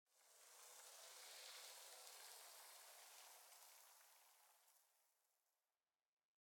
1.21.5 / assets / minecraft / sounds / block / sand / sand8.ogg
sand8.ogg